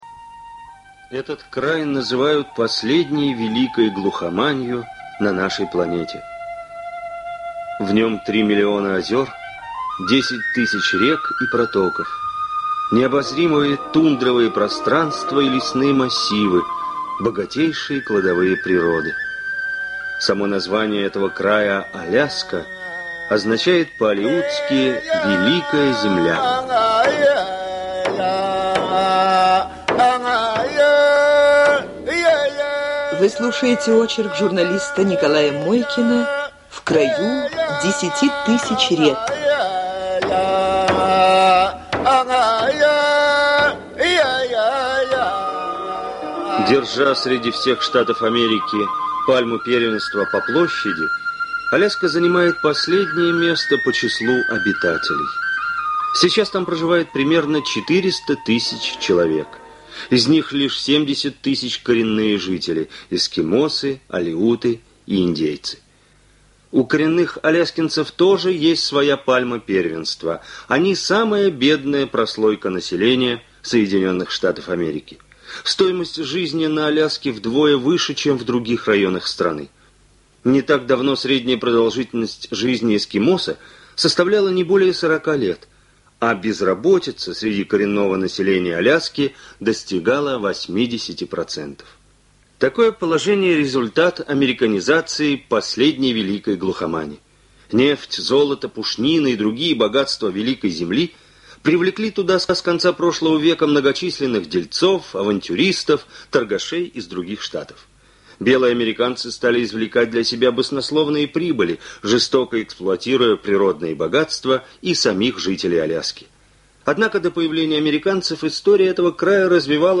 Оцифровка старой ленты.